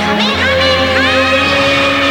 Goten's kamehameha 2 sec.